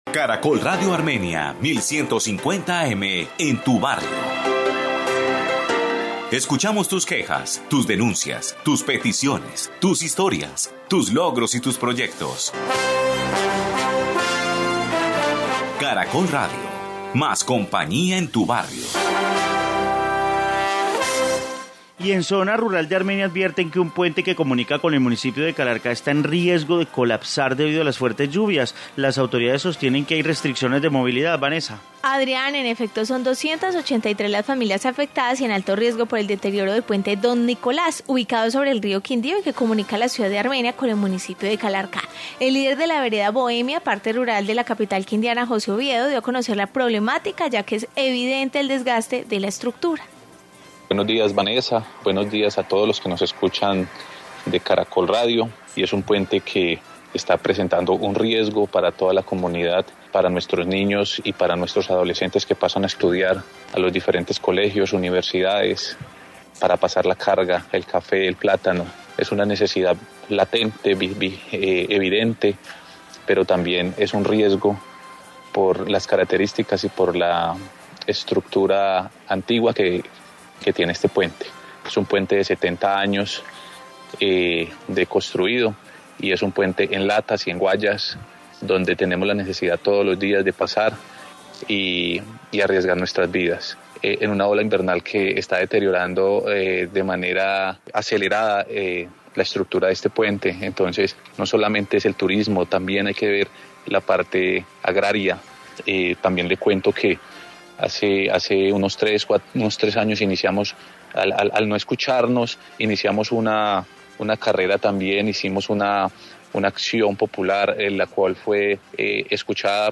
Informe puente Don Nicolás